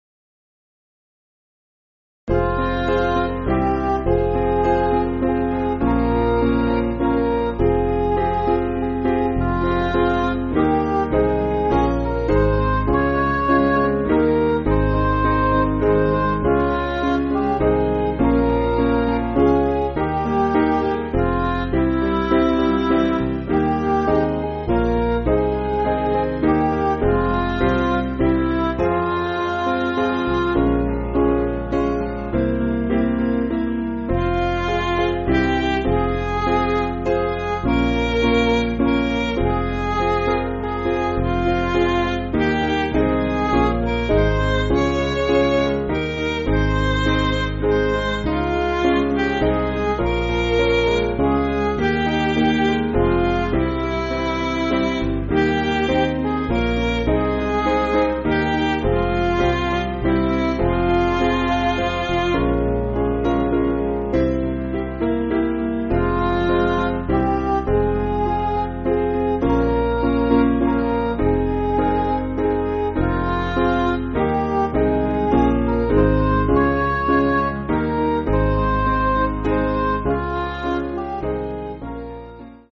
Piano & Instrumental
(CM)   4/Fm